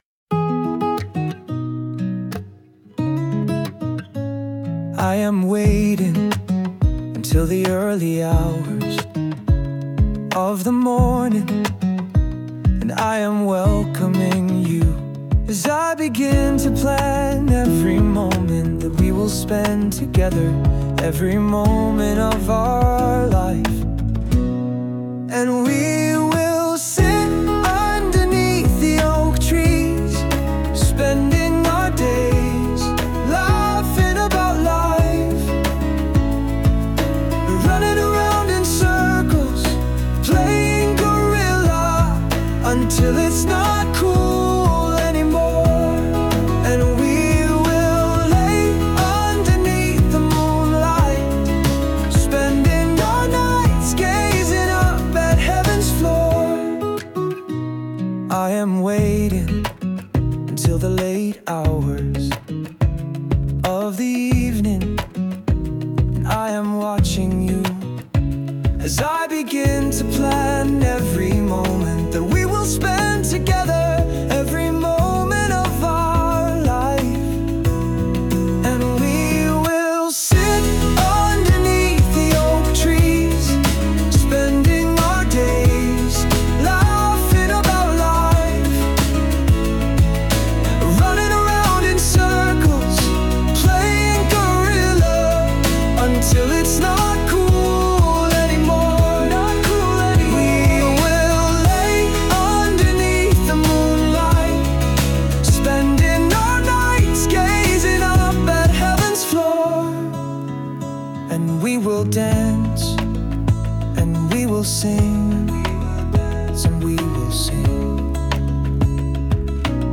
Feb 26, 2015 | Standard Tuning